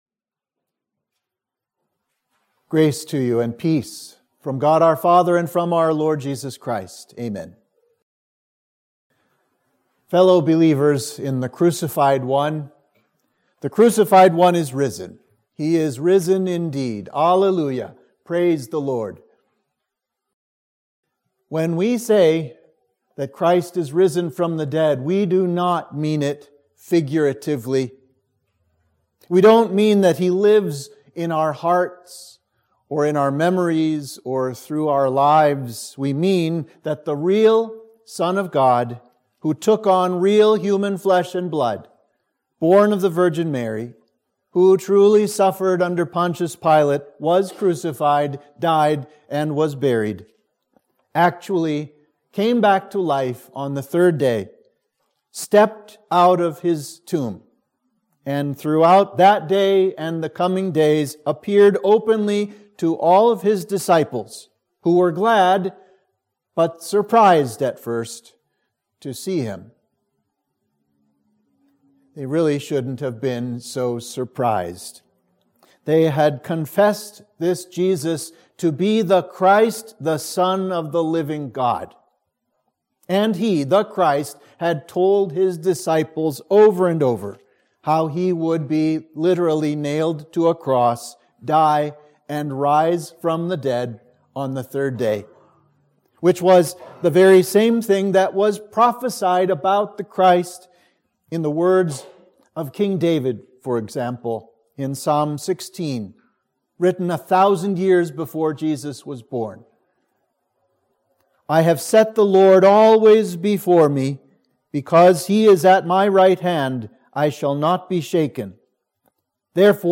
Sermon for Easter Sunday